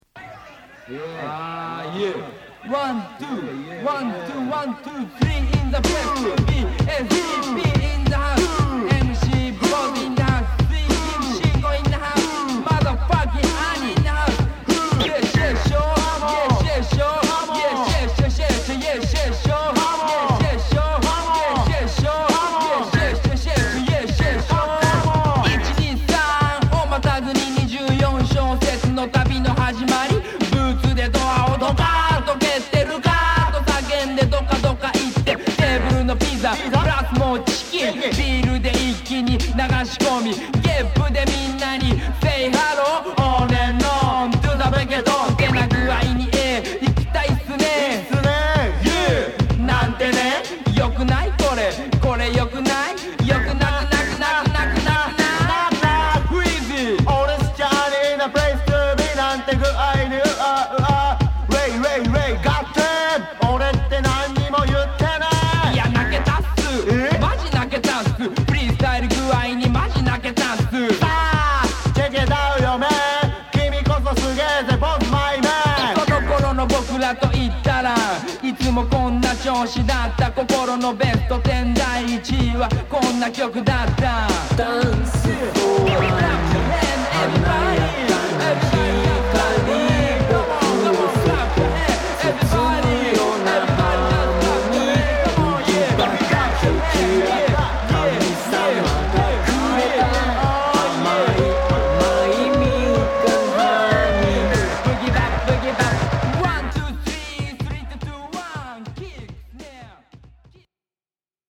のライヴテイク、ジャジーなピアノインスト
表面上の薄いスレマークは多少散見できますが、深いキズはなく殆どノイズの影響はありません。